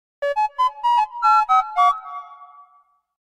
goofy android